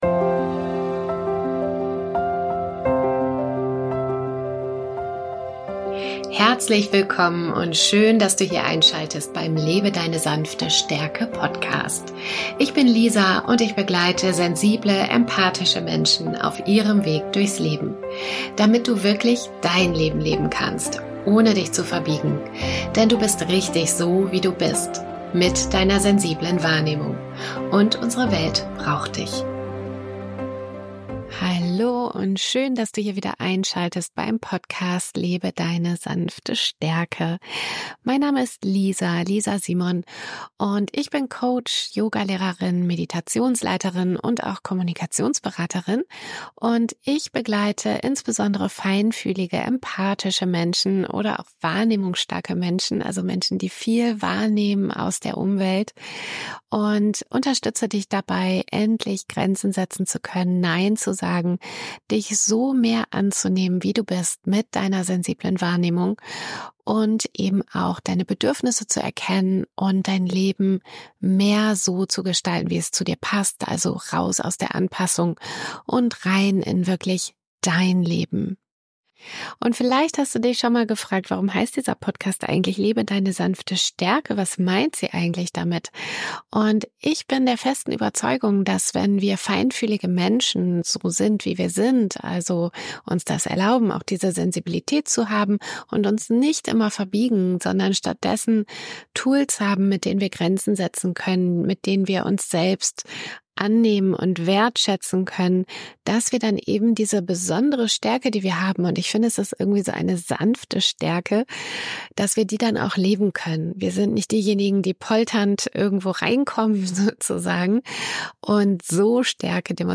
Außerdem schenke ich Dir in dieser Folge eine geführte Meditation, die Dein Bewusstsein auf Deinen Atem lenkt und zu mehr innerer Ruhe führt.